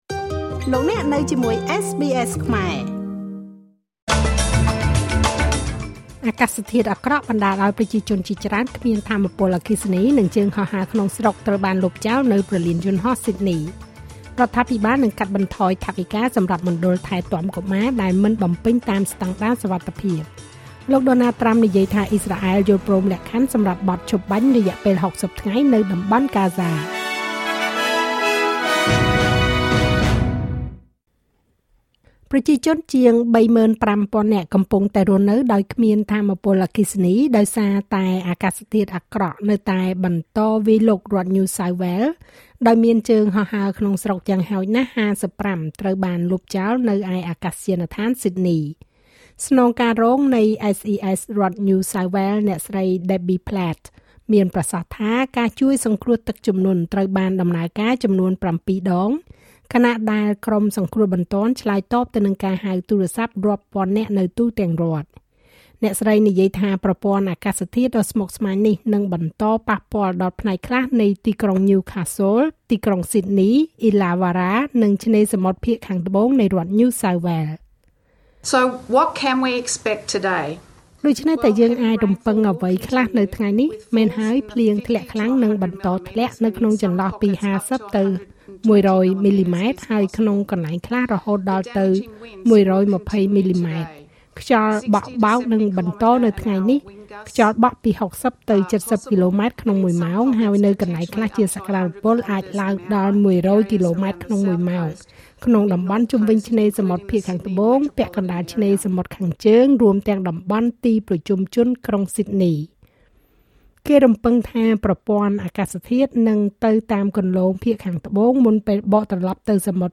នាទីព័ត៌មានរបស់SBSខ្មែរ សម្រាប់ថ្ងៃពុធ ទី២ ខែកក្កដា ឆ្នាំ២០២៥